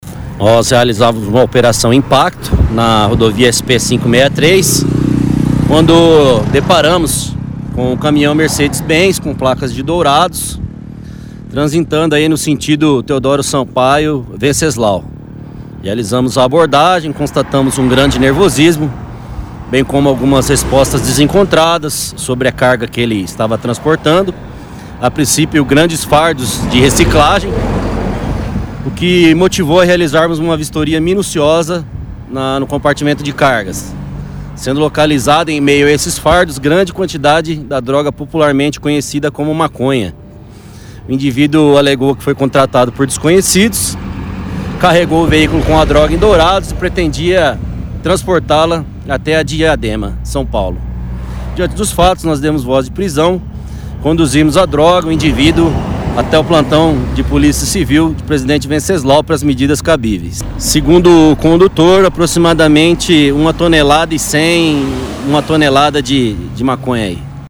Ouçam áudio do policial rodoviária explicando a apreensão.